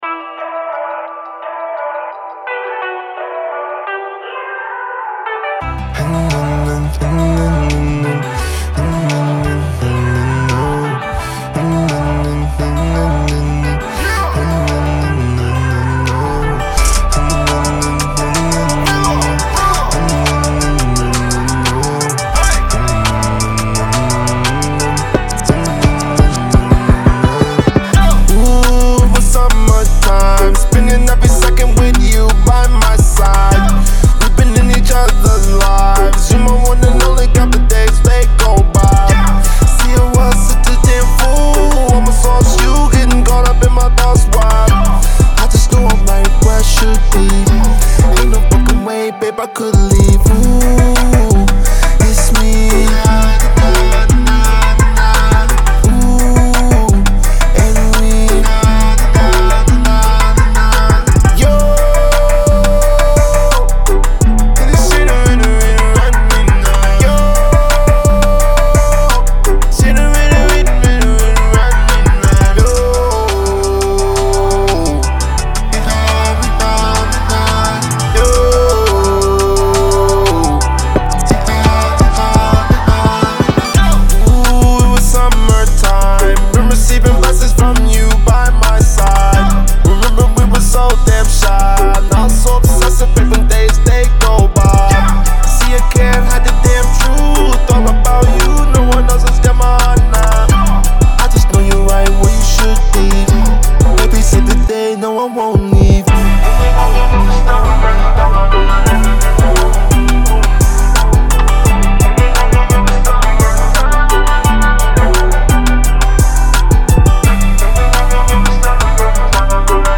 BPM86-86
Audio QualityPerfect (High Quality)
Trap song for StepMania, ITGmania, Project Outfox
Full Length Song (not arcade length cut)